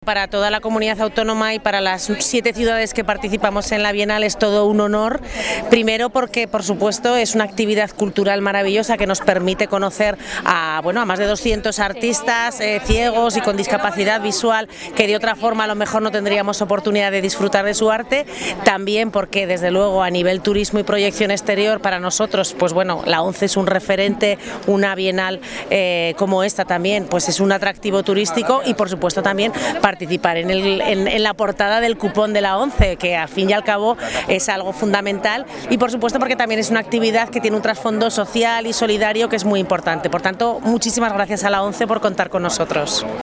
La vicealcaldesa de Zaragoza aseguró ser "un honor" para Aragón poder acoger la celebración de la Bienal, "primero porque es una actividad cultural maravillosa que nos permite conocer a estos artistas, también porque es un atractivo turístico y